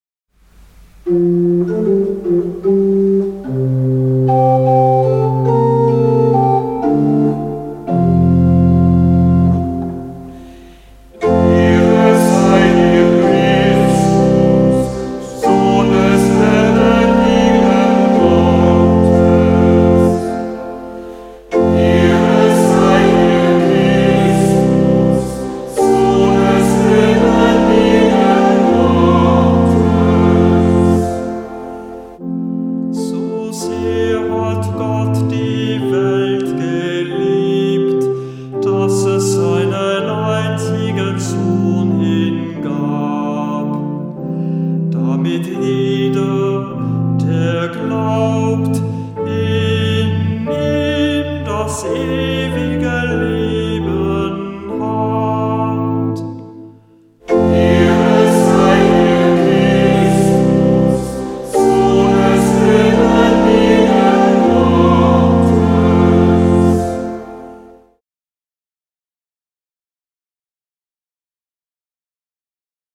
Hörbeispiele aus dem Halleluja-Büchlein
3. März 2024 - 3. Fastensonntag B, Gl 745